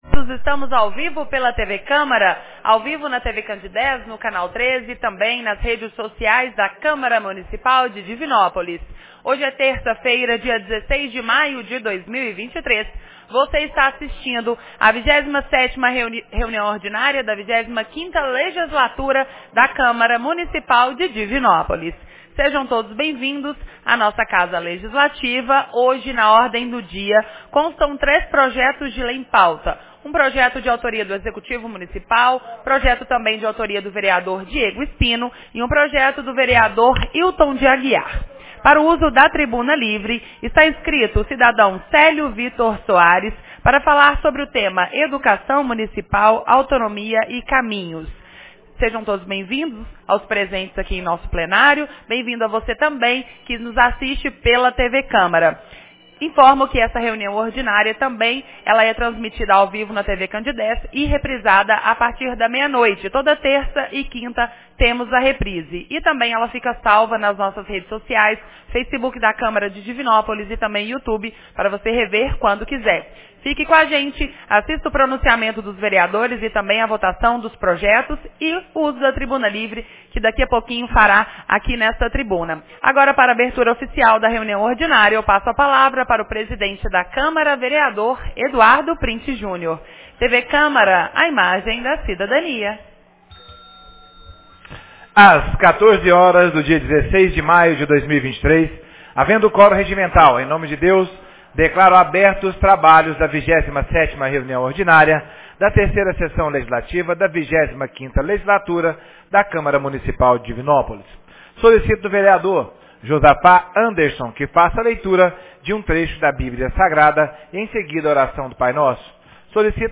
27ª Reunião Ordinária 16 de maio de 2023